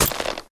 repair_wolf1.ogg